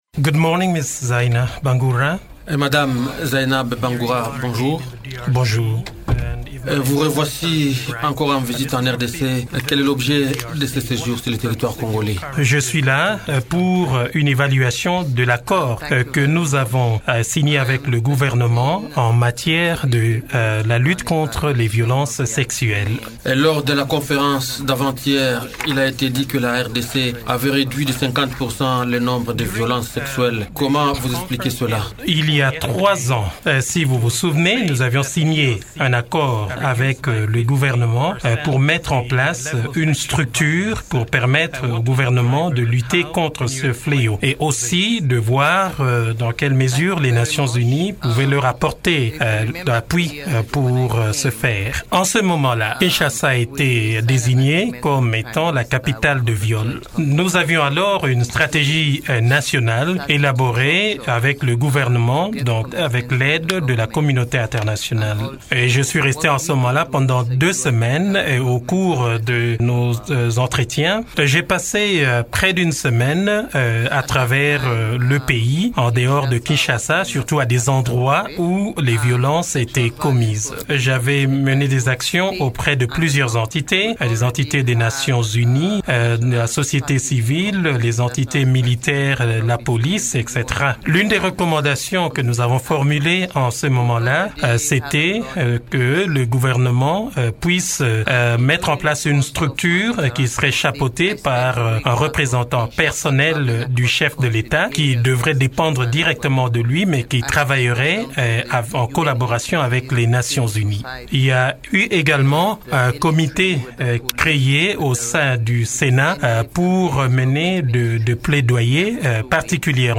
Invitée de Radio Okapi ce lundi, elle indique que ce bilan est le résultat d’un accord signé il y a trois ans entre le gouvernement et l’ONU en vue de mettre en place une structure permettant au gouvernement de lutter contre ce fléau.